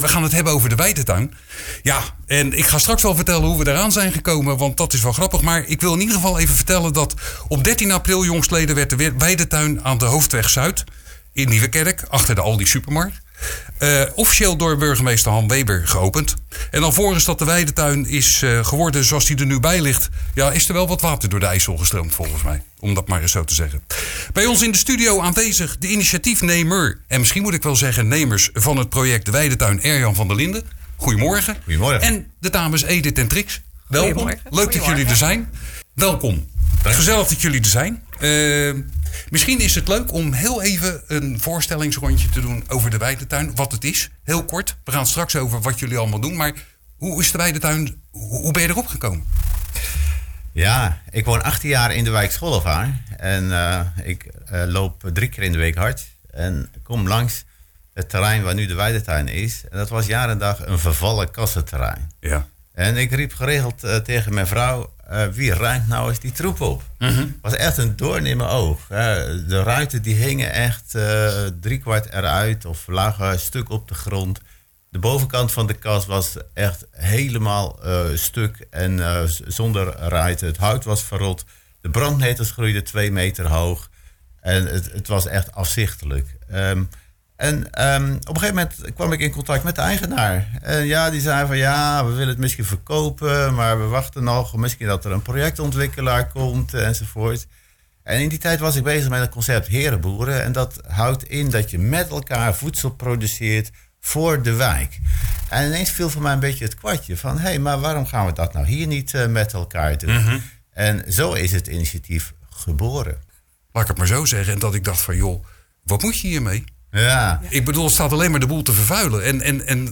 Vrijdag 5 mei waren in het programma� Kletskoek 3 gasten op bezoek die over de Weidetuinin Zuidplas (achter de begraafplaats Schollevaar) konden vertellen.